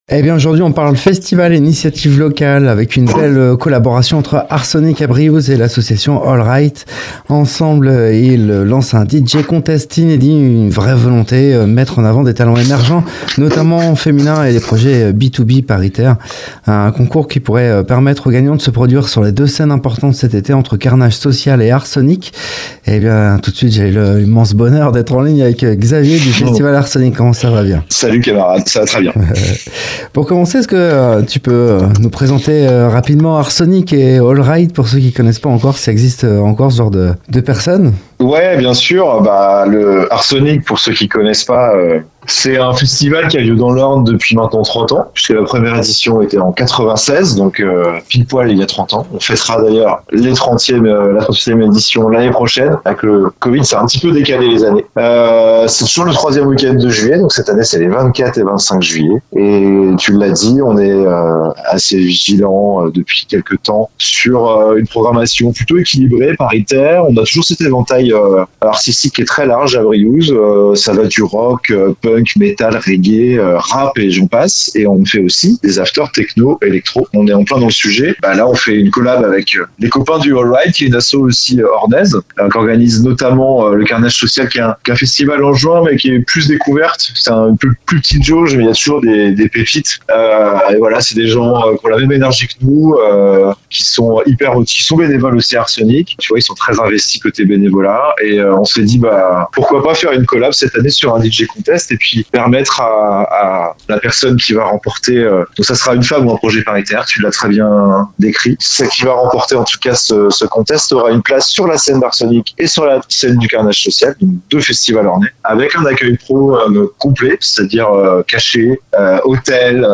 Au micro de Radio Pulse, il revient sur une initiative originale menée avec l’association Hole Right : un DJ contest inédit, pensé pour mettre en lumière les talents féminins et les projets paritaires.